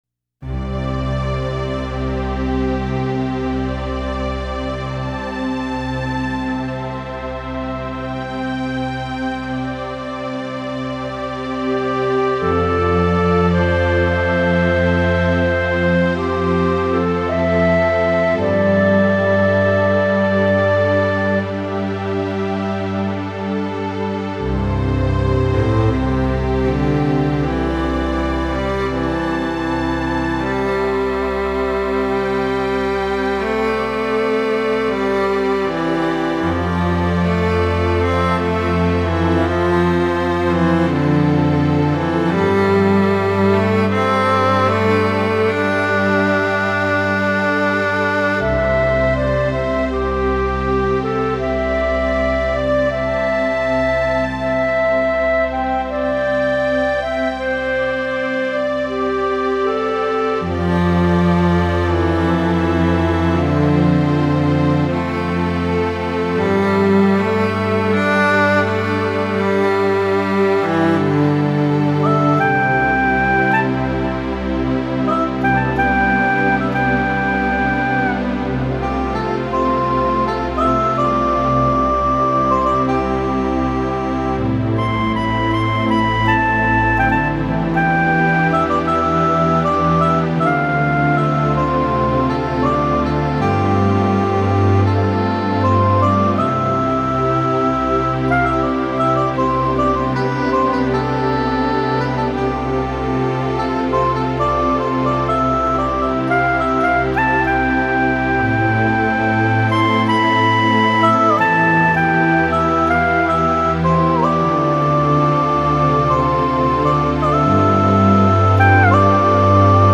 佛教专题
能有安神、助眠,减低压力安抚烦躁的功效.